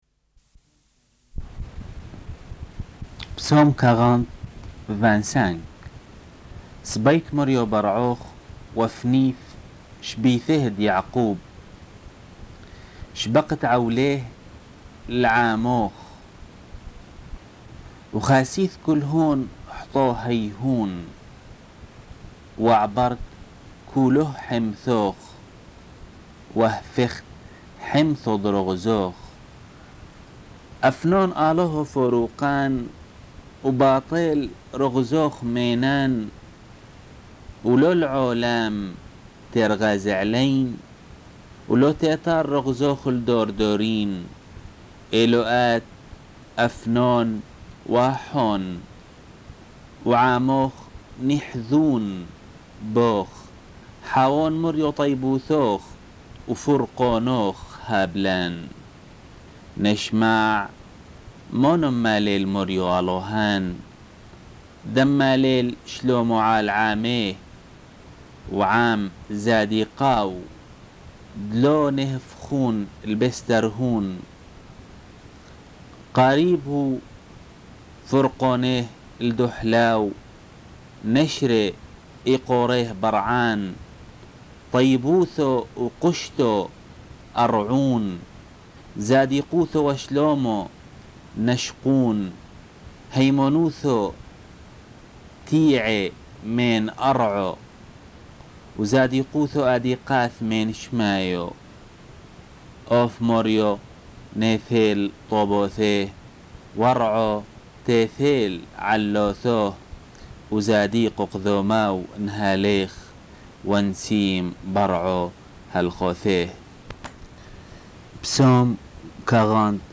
Enregistrement de la lecture des Psaumes (version syriaque)